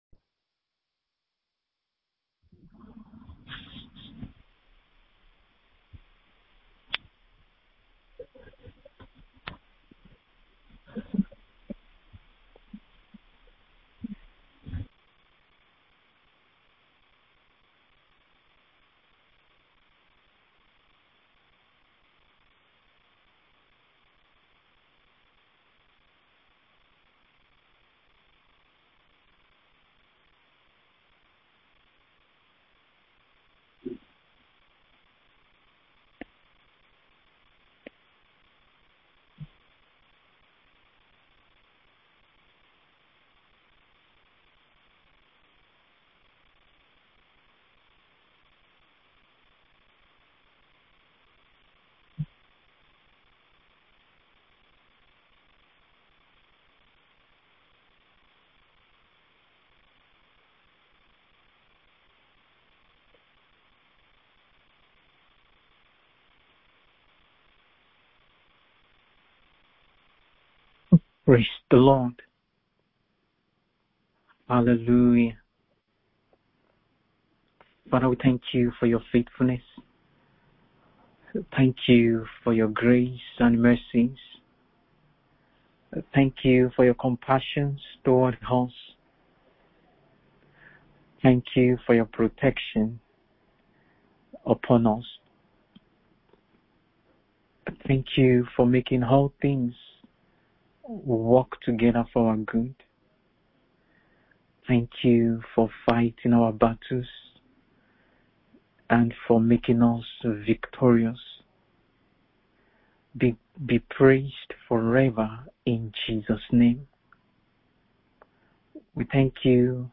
BIBLE STUDY _ REASONS WHY PERSECUTORS ATTACK CHRISTIANS – 1) FOR REFUSING TO COMPROMISE FAITH_2) FOR REFUSING TO WORSHIP THE ANTICHRIST AND HIS IMAGE